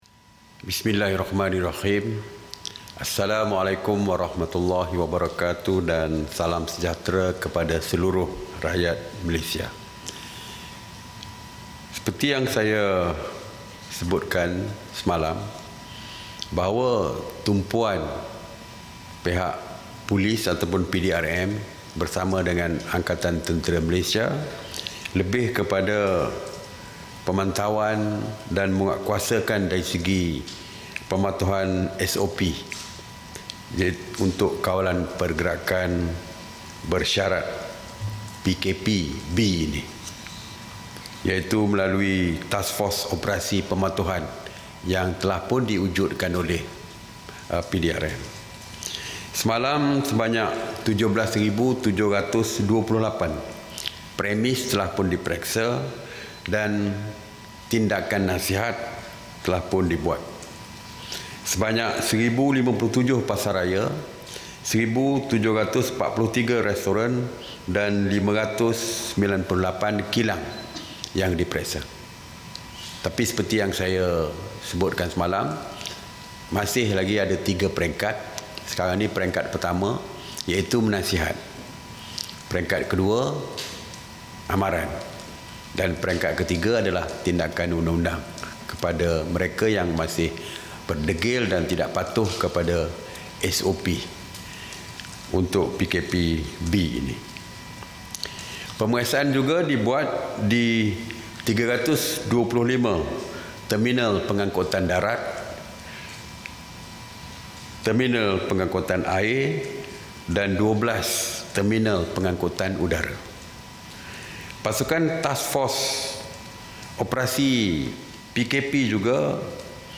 Ikuti sidang media Menteri Kanan Keselamatan, Datuk Seri Ismail Sabri Yaakob berkaitan Perintah Kawalan Pergerakan.